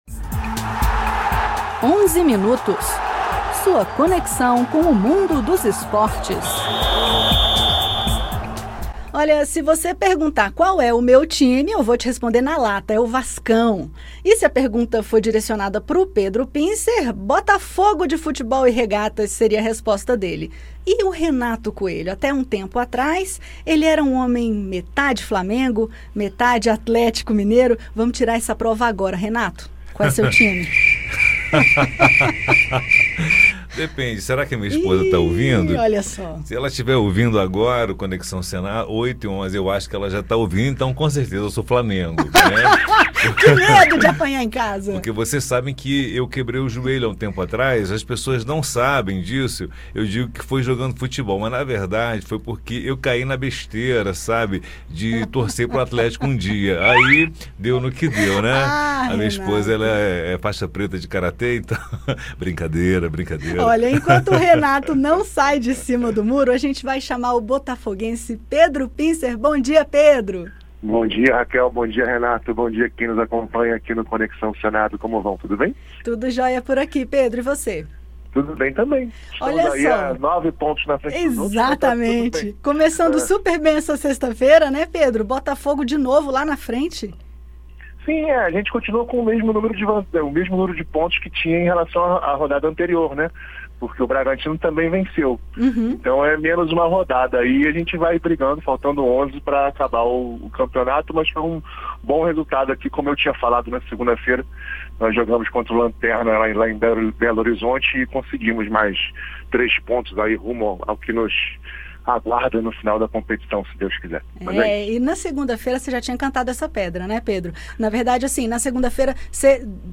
Nos comentários esportivos, as últimas sobre a Seleção, as rodadas da Série A e a decisão da Série C. Ouça ainda: Neymar fora dos gramados por pelo menos 6 meses, os problemas que envolvem o Maracanã na final da Libertadores e o início dos Jogos Pan-Americanos em Santiago.